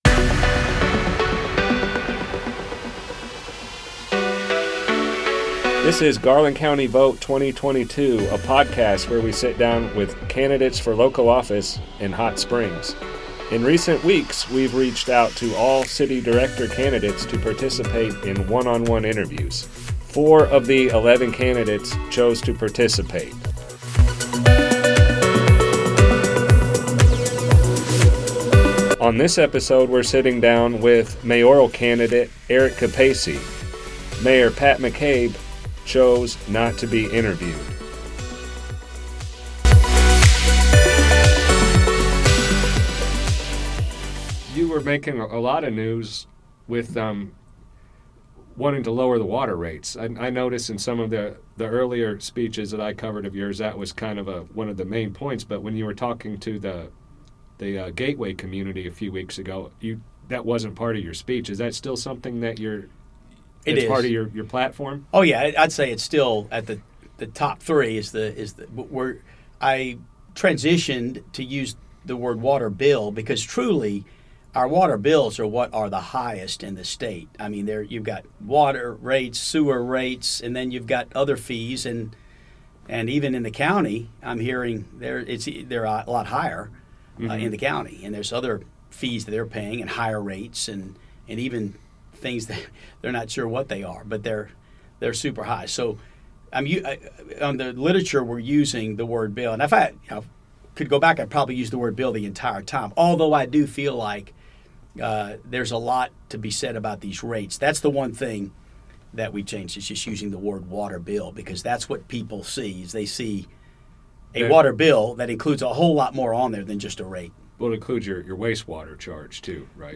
These podcasts are airing in their entirety. No edits were made to the content.